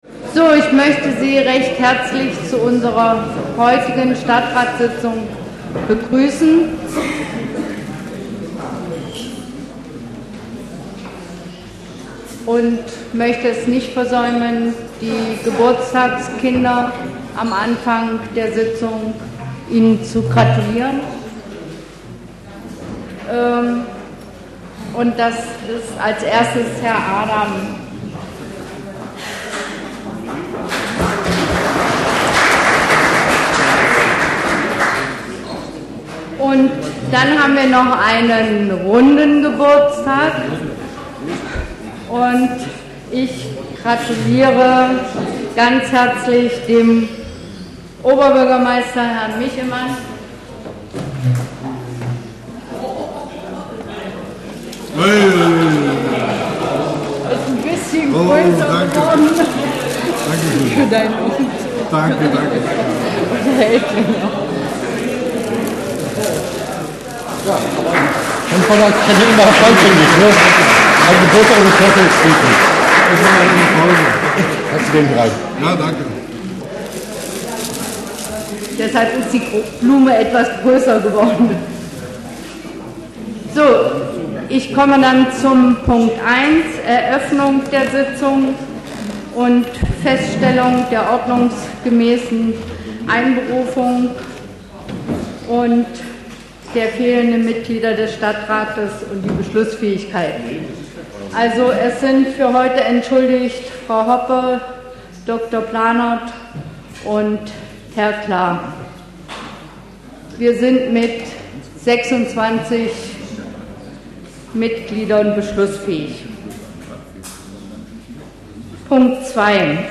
Stadtratssitzung in Aschersleben
radio hbw strahlt regelmäßig die Sitzungen des Ascherslebener Stadtrats aus. Diesmal ging es unter anderem um die Jahresabschlüsse der städtischen Gesellschaften, um Ausbaubeiträge in Mehringen und Winningen, um die ausgedörrten Bäume auf der Alten Burg, um die Sanierung des Montessori-Kinderhauses im Stadtpark und um die Verlegung des Radweges zwischen Aschersleben und Westdorf.